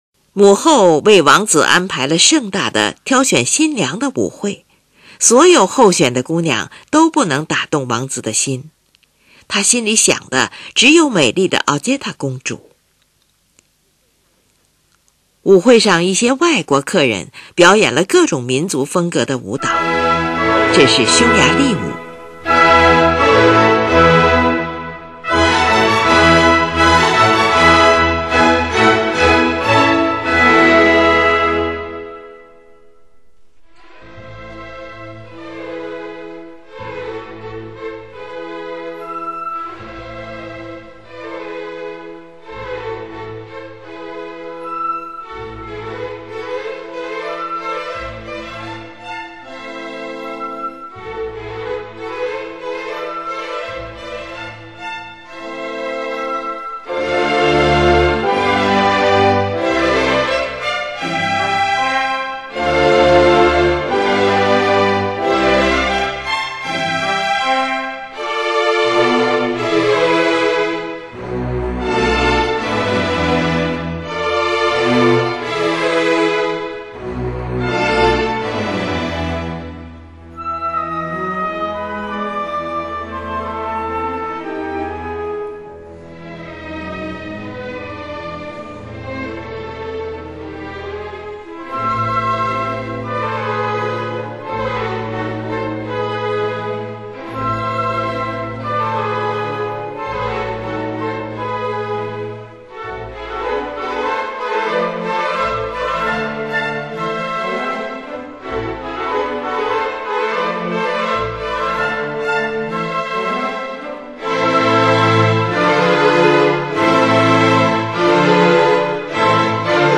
音乐的前半段舒缓而伤感，如舞蹈前的准备，音乐后半段节奏强烈，显示出舞蹈者的粗犷，是一首狂热的舞曲。